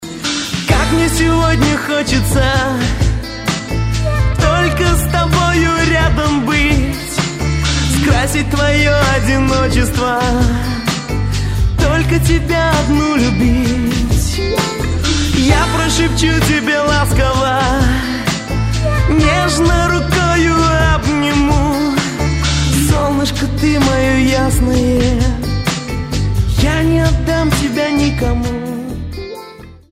Поп , Ретро